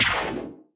death_3.ogg